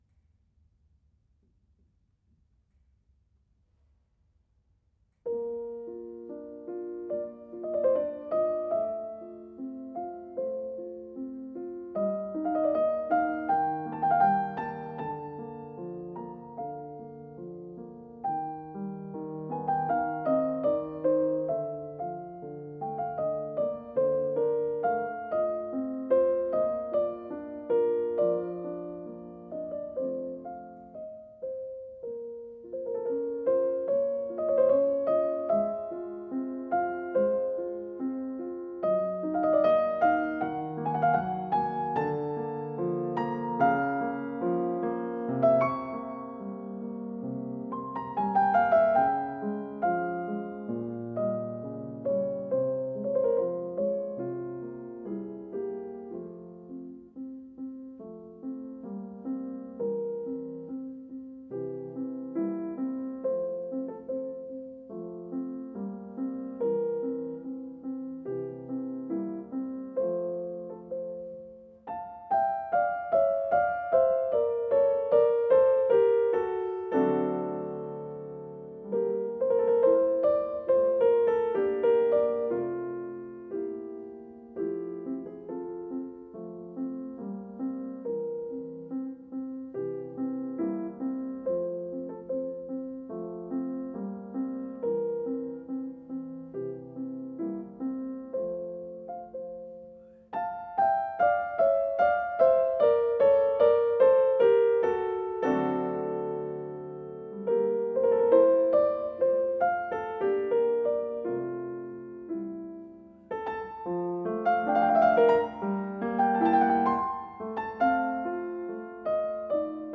Позвольте внести свою лепту  В.А. Моцарт "Piano Sonata in F major, KV 332 II. Adagio", но с вопросом.